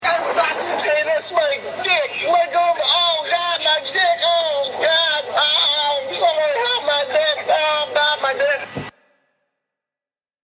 Tags: hoodrat profanity funny yelling